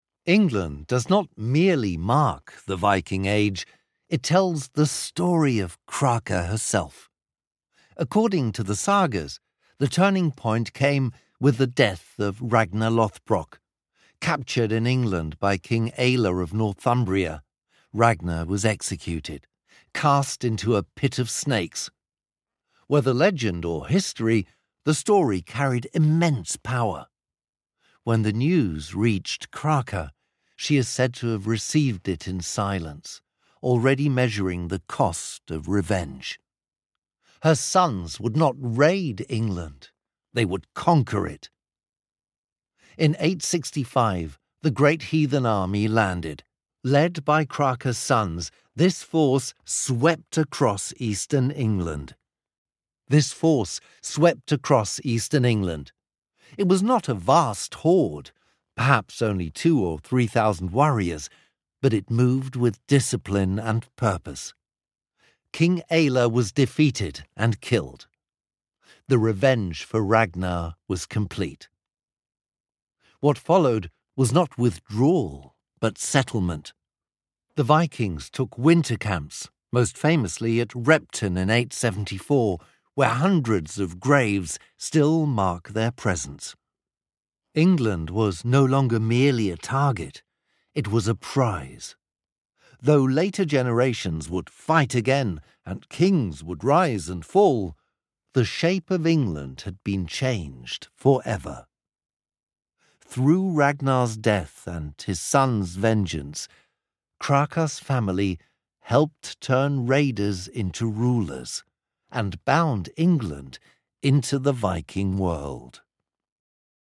voiceover-native-speaker-uk-british-englischer-sprecher-viking-alegria-exhibition.mp3